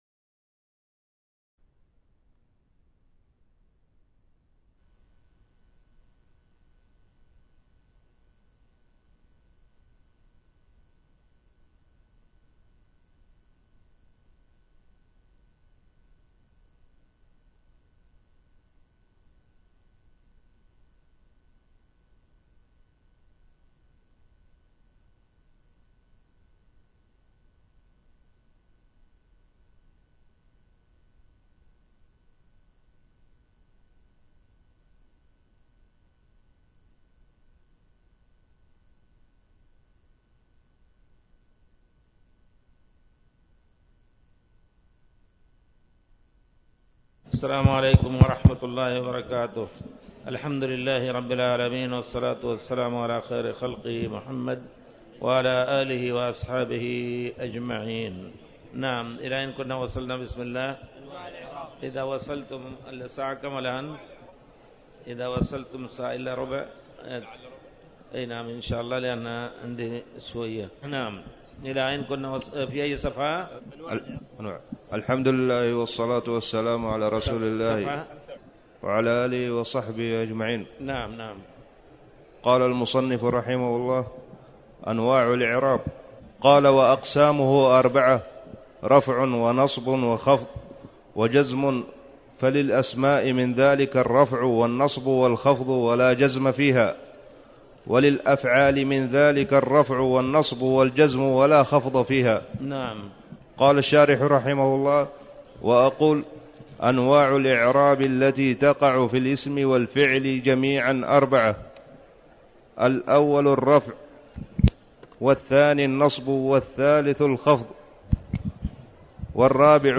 تاريخ النشر ٢٢ جمادى الآخرة ١٤٣٩ هـ المكان: المسجد الحرام الشيخ